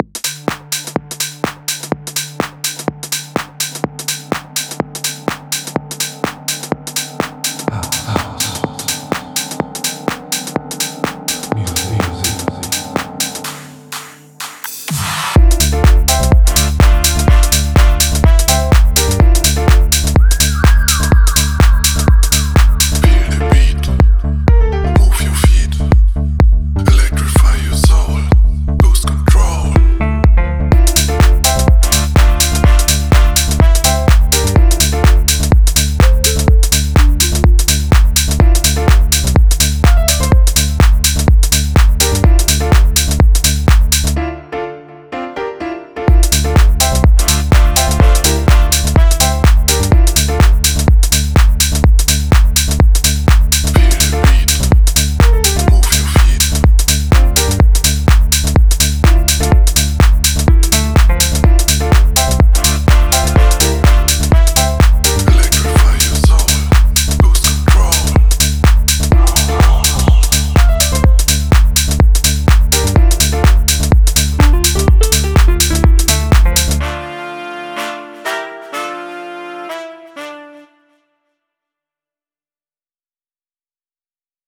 House Contest 2023 - 1 ABGABESCHLUSS AM 23.04.2023 um 20:00
Ist so 80s Koks Party vibe. was man hier so alles erfährt :wayne:
Habe total Lust drauf, und schnell einen Beat und ein paar Loops zusammen geschubst.
Fehlt natürlich auch der Vocal Lead Part usw.
Dafür ist drin: M1 Orgel, Klavier und ne Gitarre, weil ich eine D.I. Box ausprobieren wollte.